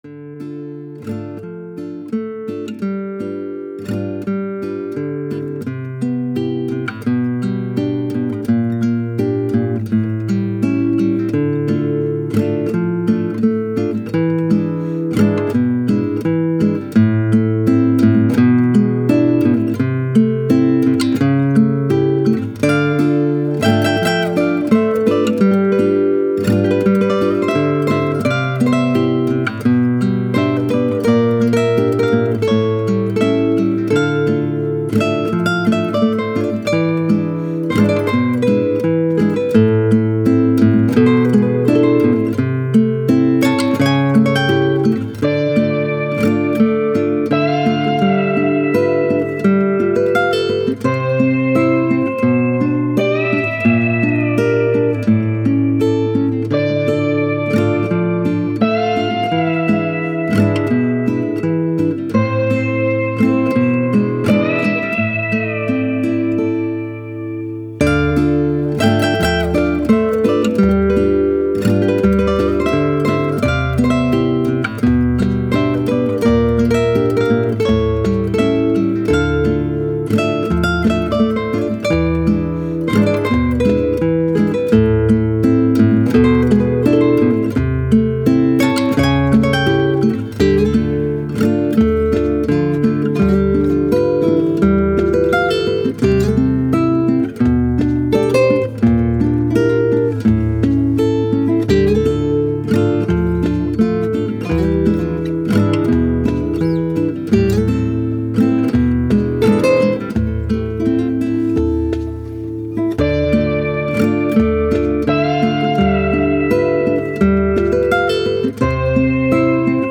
Spanish, Classical, Guitar, Melancholic, Emotive